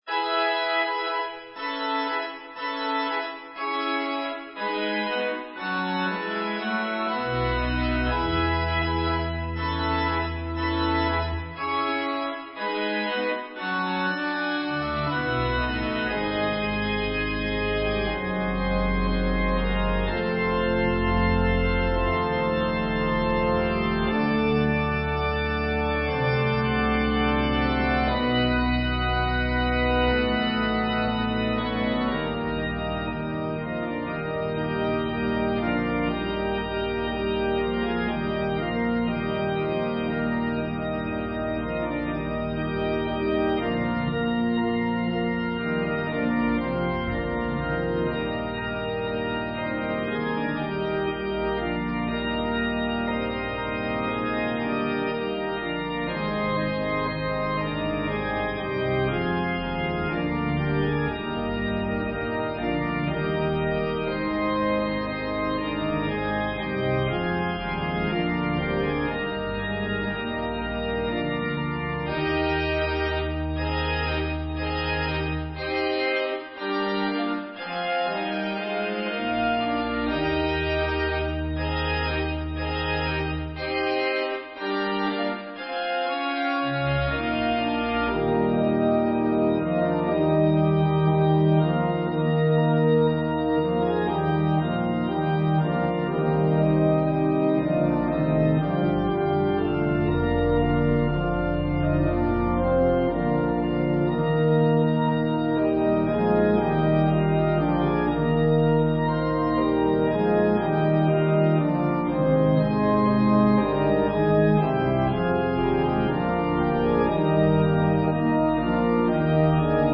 An organ solo version
Christmas hymn
Voicing/Instrumentation: Organ/Organ Accompaniment